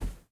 step_carpet.ogg